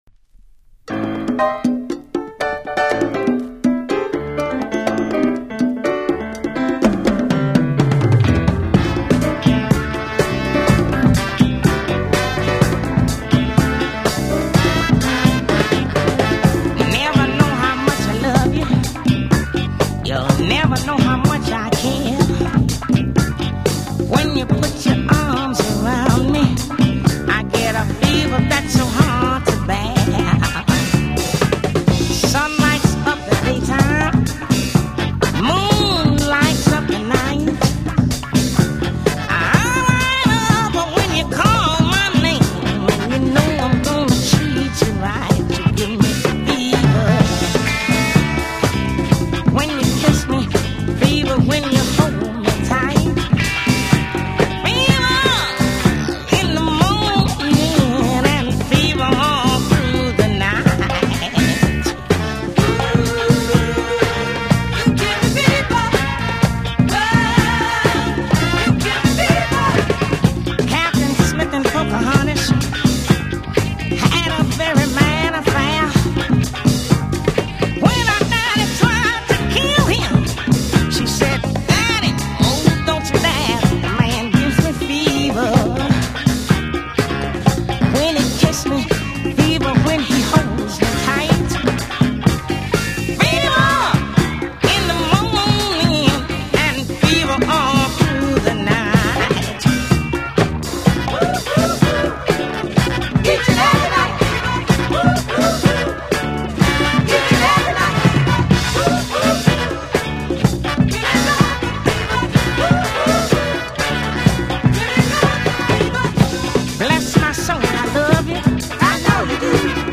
SOUL-FUNK